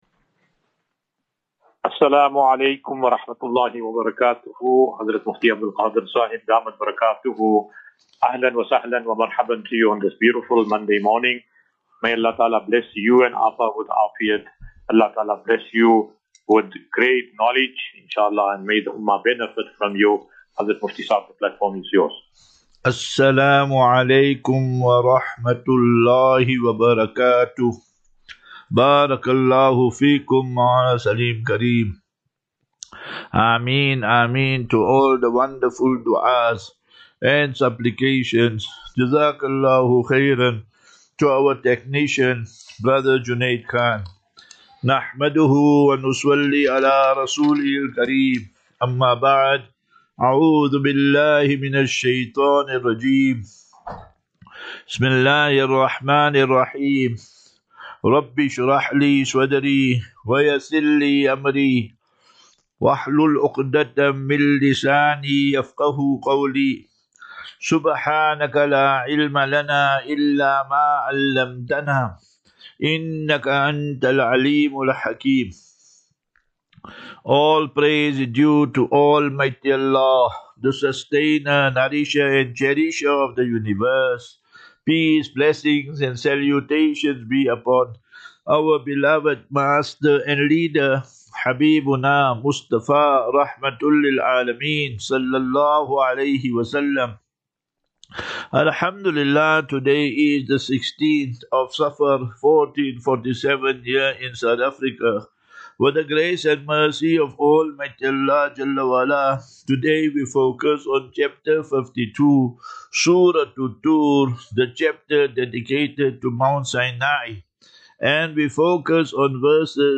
As Safinatu Ilal Jannah Naseeha and Q and A 11 Aug 11 August 2025.